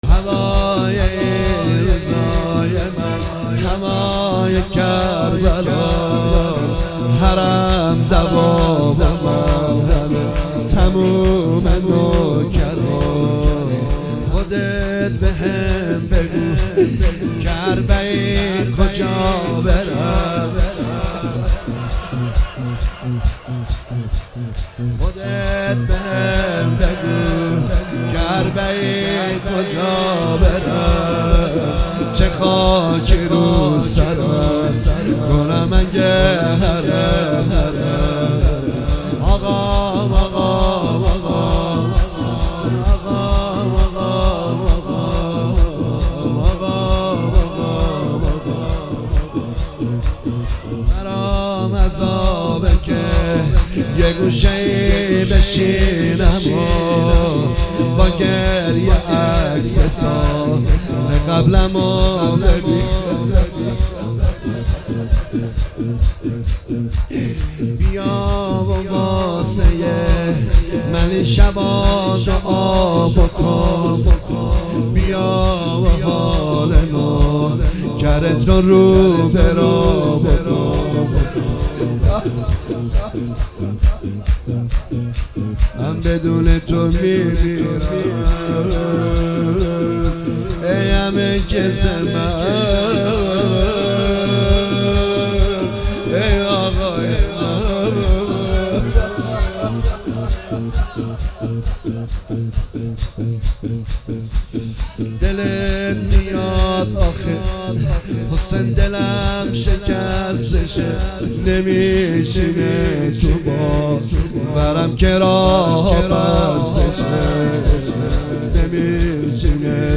مداحی دلنشین